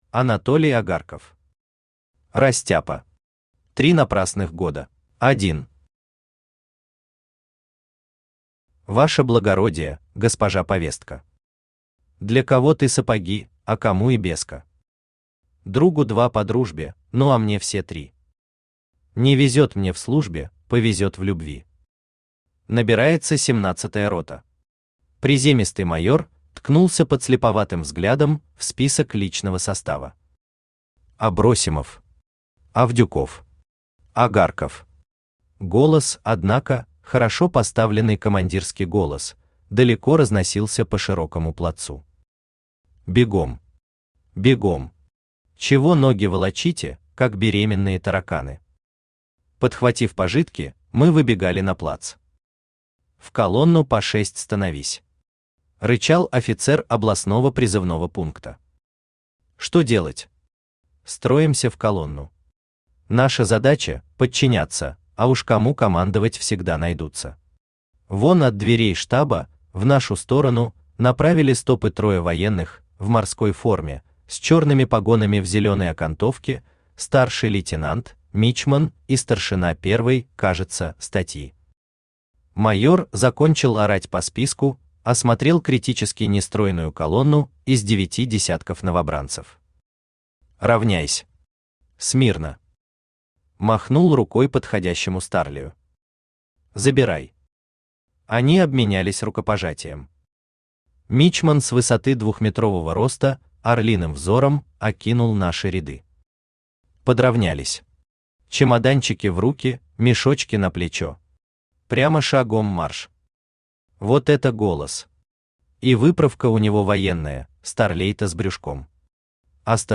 Аудиокнига Растяпа. Три напрасных года | Библиотека аудиокниг
Три напрасных года Автор Анатолий Агарков Читает аудиокнигу Авточтец ЛитРес.